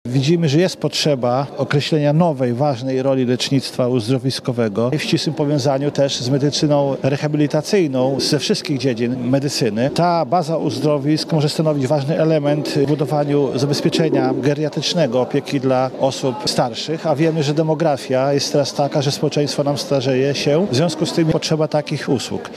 Jak zauważa poseł na Sejm Sławomir Zawiślak – medycyna uzdrowiskowa pozwala lepiej wykorzystać potencjał opieki rehabilitacyjnej w Polsce.